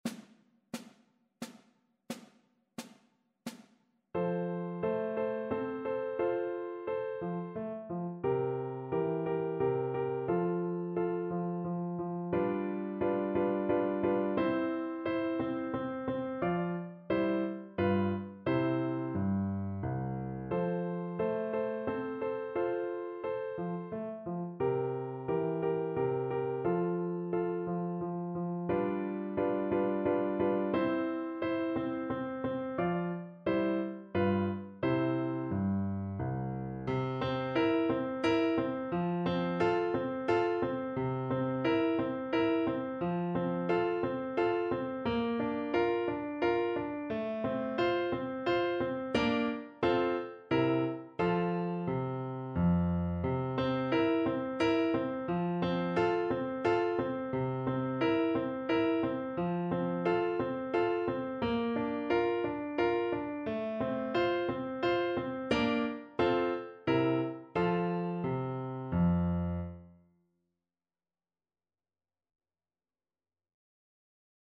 Mozart: Menuet z opery Don Giovanni (na flet i fortepian)
Symulacja akompaniamentu